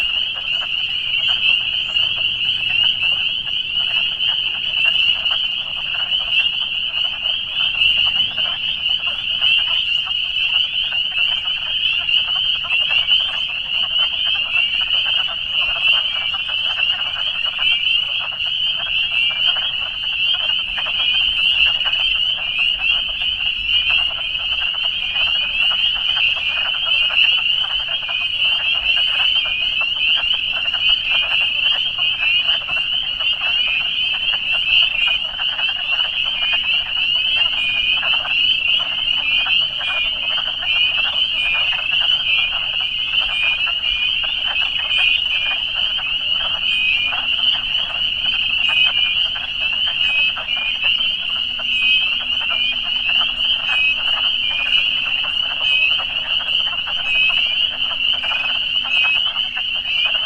Wood Frogs
A few days after my encounter with the hawk, I revisited the marsh.
I was fortunate to record both spring peepers and wood frogs. I’d been listening to and enjoying the high-pitched sounds of the peepers, when all of a sudden the wood frogs announced their presence. Their voices, which sound like quacking, were almost drowned out.
wood-frogs.wav